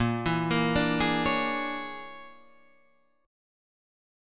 A#7#9b5 Chord